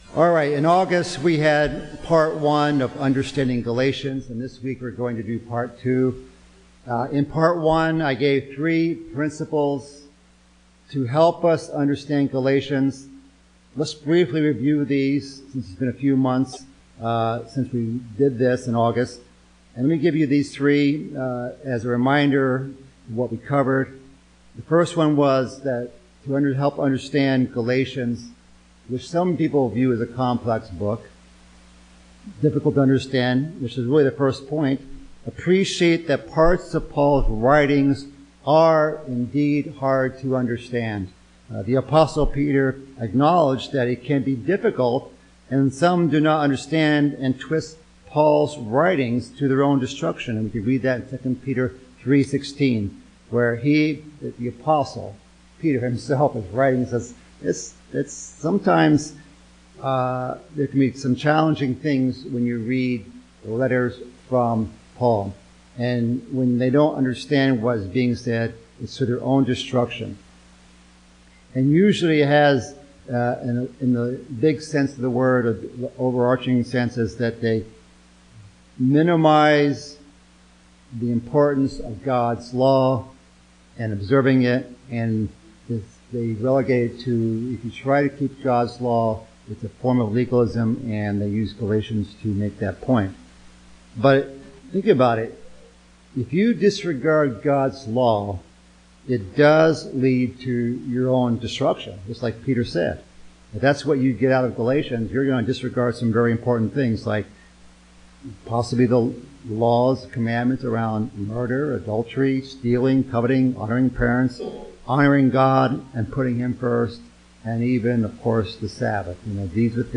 Part two in a sermon series on Galatians.